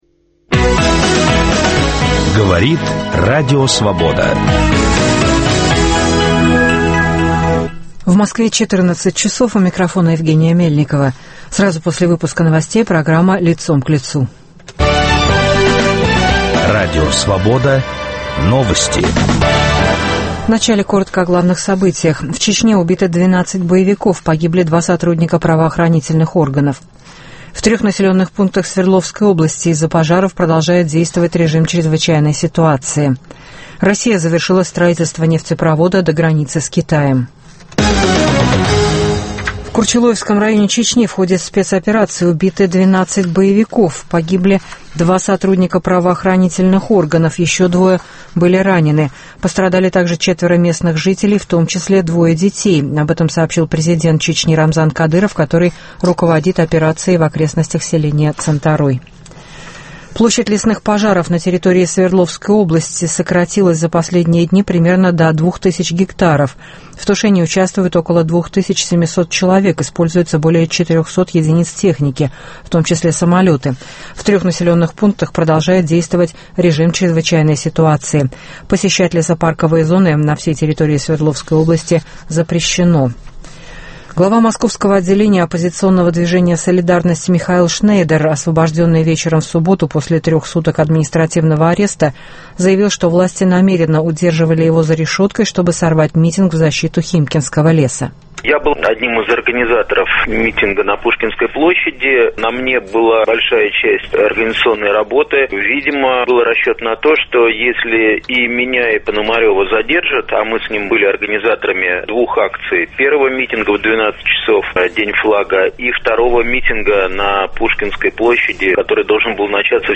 Гость передачи – режиссер, актер и композитор Петр Тодоровский.